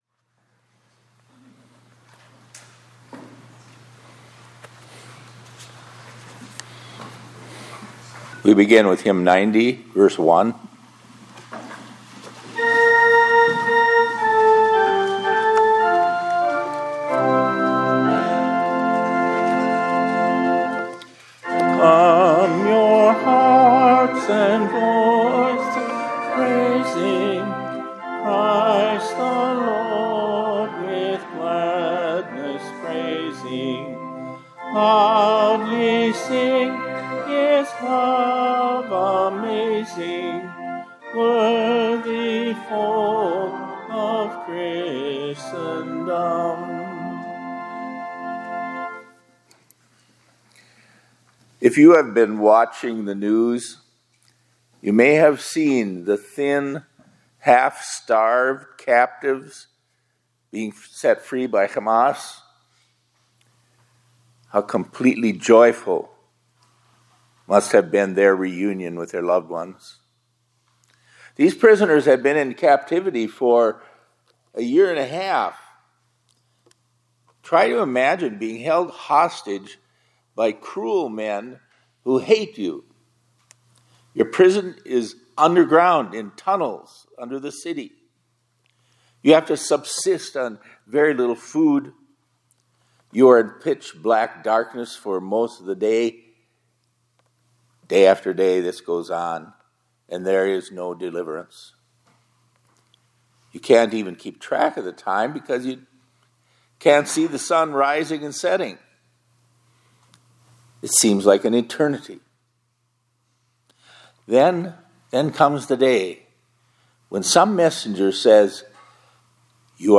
2025-02-27 ILC Chapel — Ours is a Glorious Deliverance